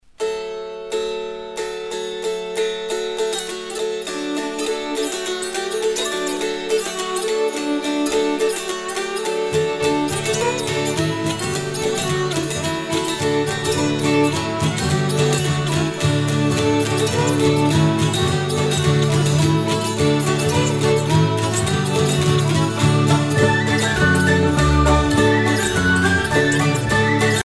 Groupe celtique